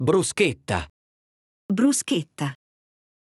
Szerintem mindenki ismeri az egyik leghíresebb olasz előételt, a paradicsomos pirítóst, azaz a bruschetta-t. Kiejtése “bruszketta” és nem “brusetta”!